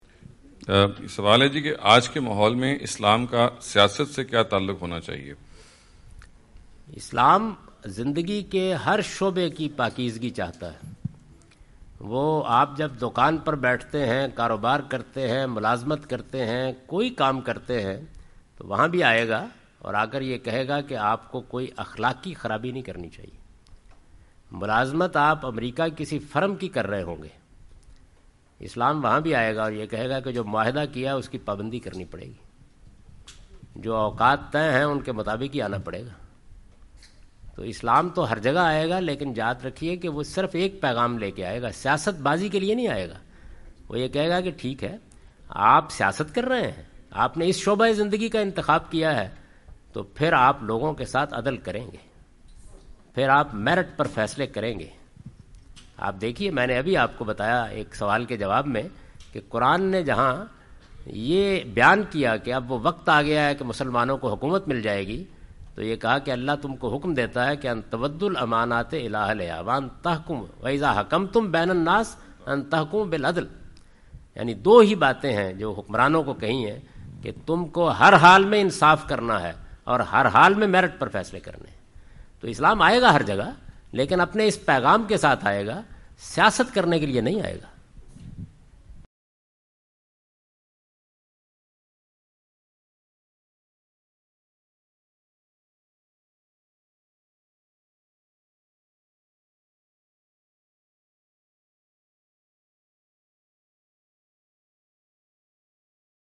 Category: English Subtitled / Questions_Answers /
Javed Ahmad Ghamidi answer the question about "Islam and Politics" during his US visit.
جاوید احمد غامدی اپنے دورہ امریکہ کے دوران ڈیلس۔ ٹیکساس میں "اسلام اور سیاست" سے متعلق ایک سوال کا جواب دے رہے ہیں۔